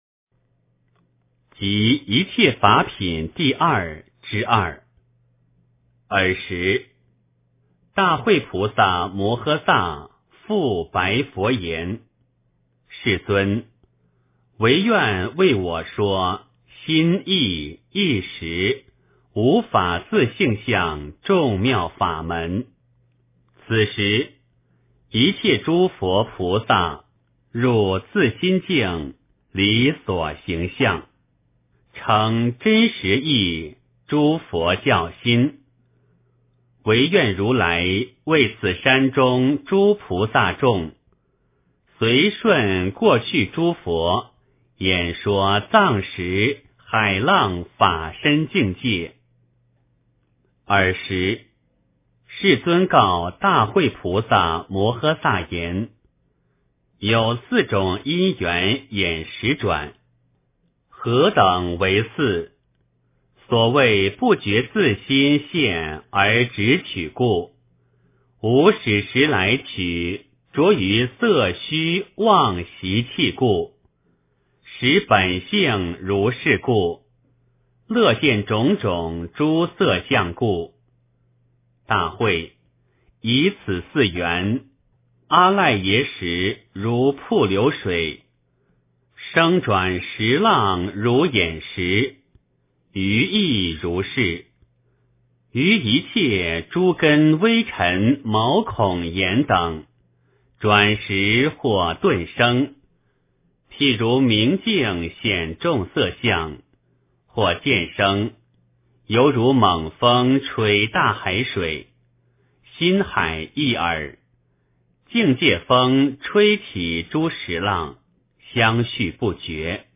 楞伽经（一切法品第二之二） 诵经 楞伽经（一切法品第二之二）--未知 点我： 标签: 佛音 诵经 佛教音乐 返回列表 上一篇： 金刚般若波罗蜜经 下一篇： 楞伽经（一切法品第二之三） 相关文章 貧僧有話37說：我一生“与病为友” 貧僧有話37說：我一生“与病为友”--释星云...